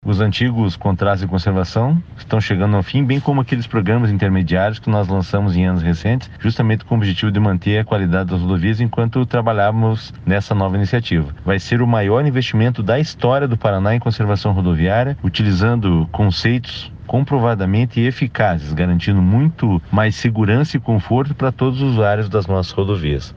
Sonora do diretor-geral do DER/PR, Fernando Furiatti, sobre a importância dos programas de conservação do pavimento das rodovias paranaenses